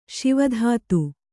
♪ Śiva dhātu